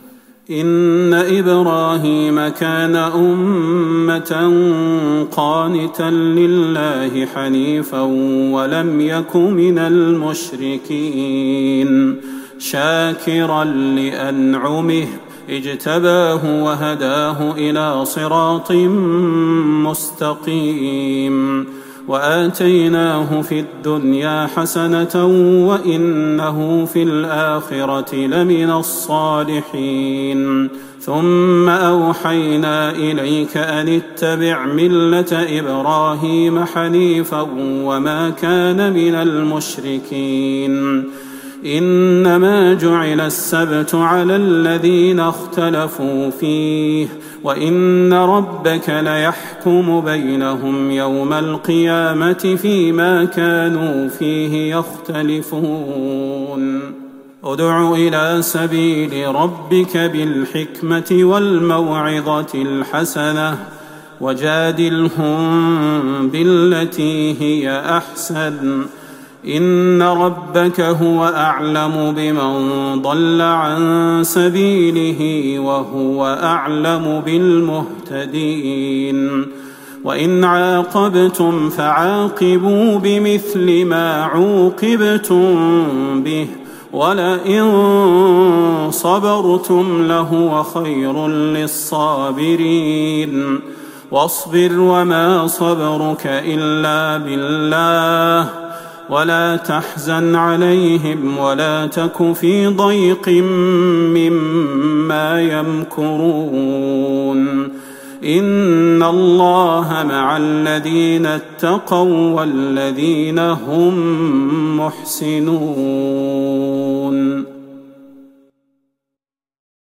ليلة ١٩ رمضان ١٤٤١هـ من سورة النحل { ١٢٠-١٢٨ } والإسراء { ١-٤٨ } > تراويح الحرم النبوي عام 1441 🕌 > التراويح - تلاوات الحرمين